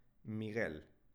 spanische-jungennamen-miguel.wav